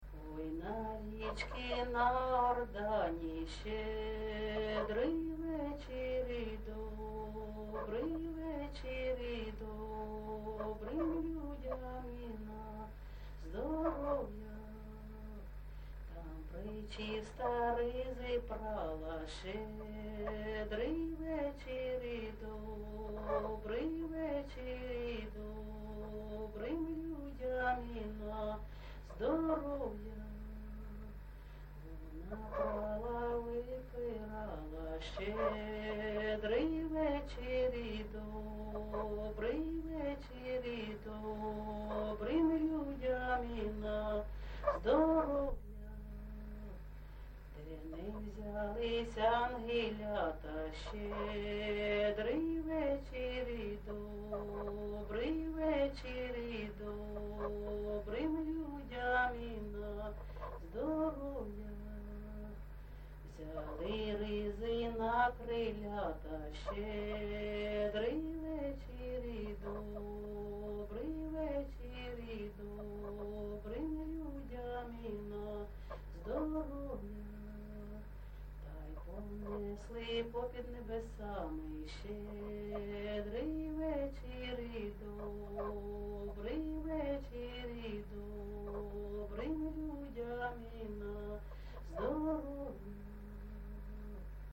ЖанрЩедрівки
Місце записус. Званівка, Бахмутський район, Донецька обл., Україна, Слобожанщина